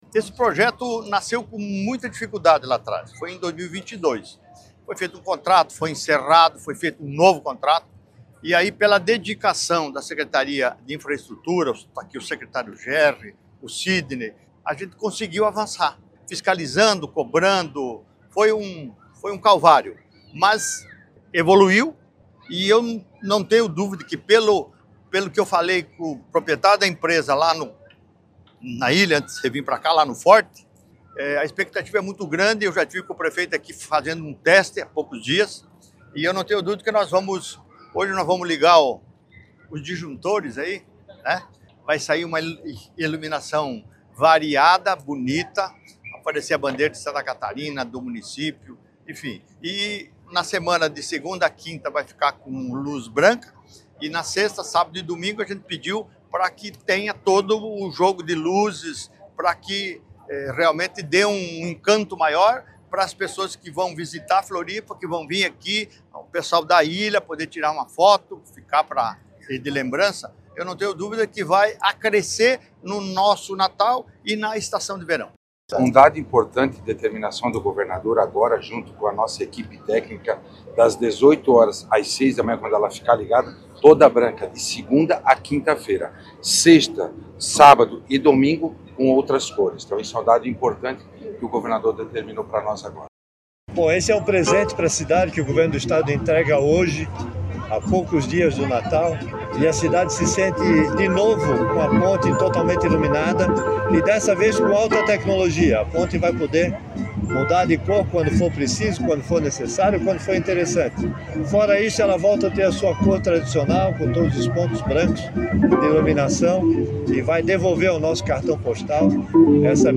Antes da inauguração, ele falou sobre o trabalho para deixar o cartão-postal de Santa Catarina ainda mais bonito:
SECOM-Sonoras-Inauguracao-iluminacao-Ponte-Hercilio-Luz-2.mp3